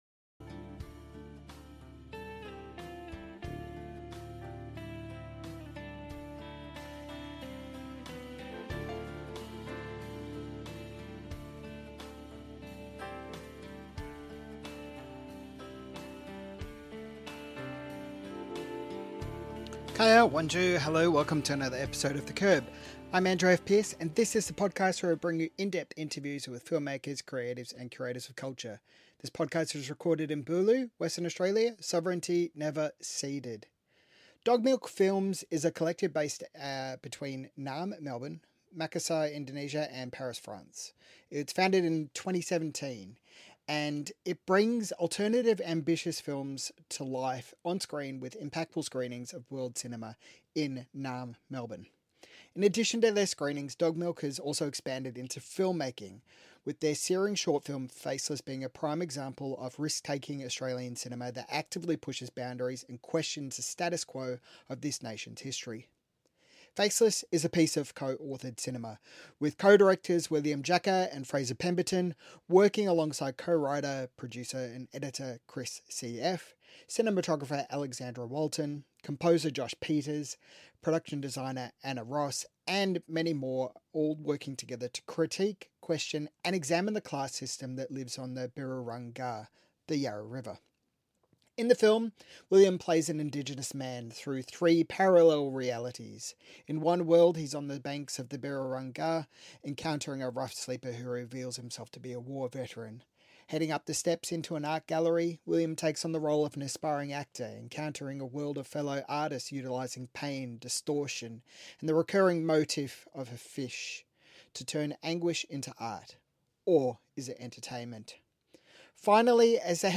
MIFF Interview